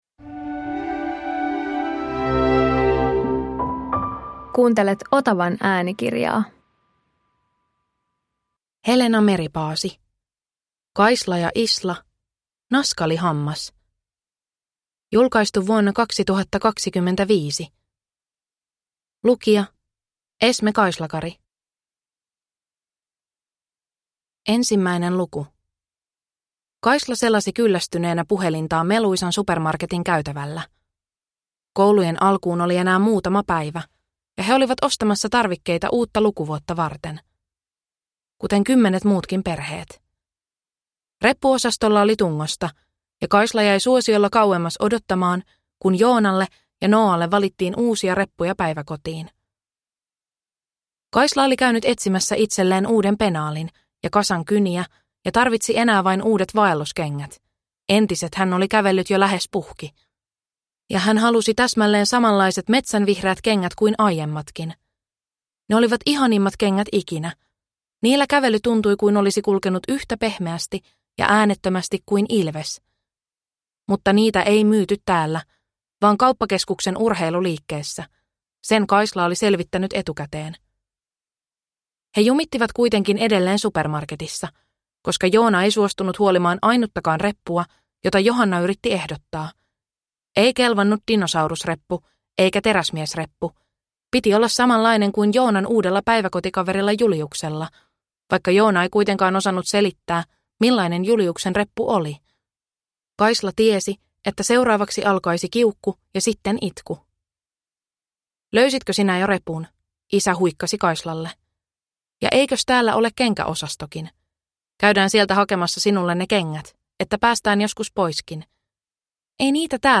Kaisla ja Isla - Naskalihammas – Ljudbok